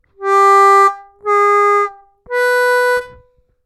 отрывисто в обратную сторону +3 -3'''-3